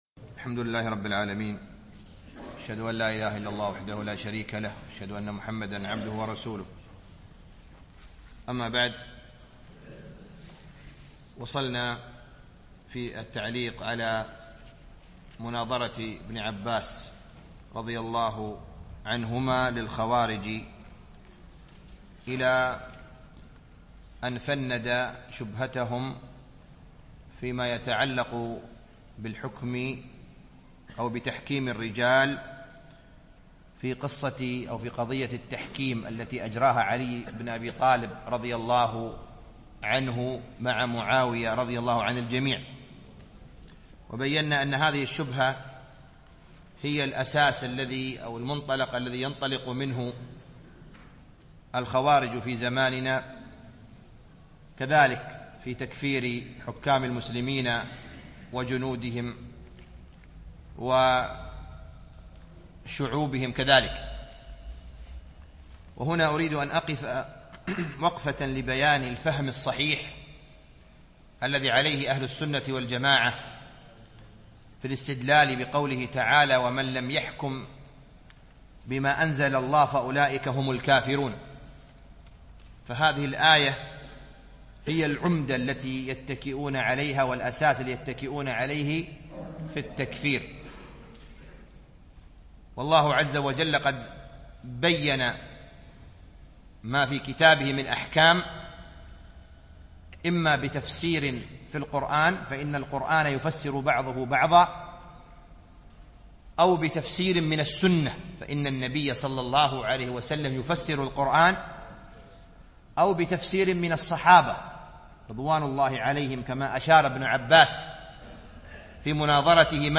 التعليق على نصيحة ابن عباس للخوارج ـ الدرس الثالث